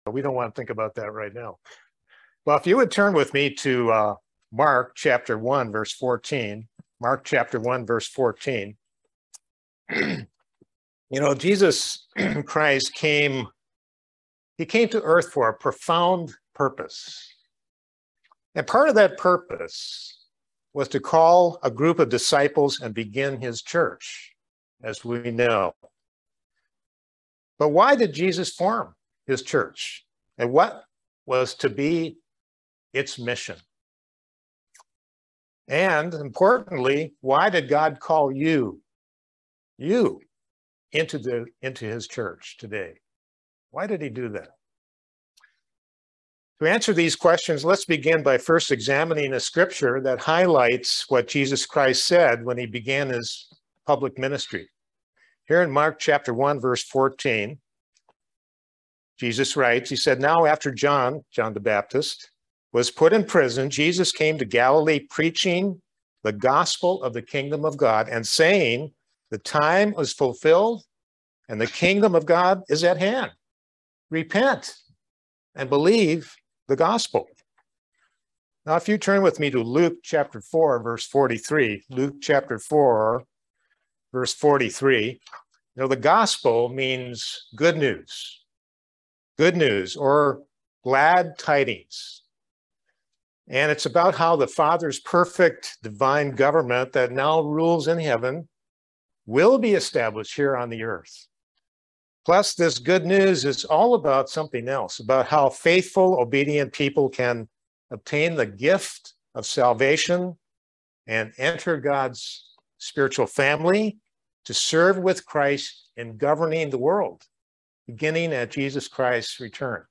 Sermons
Given in Lexington, KY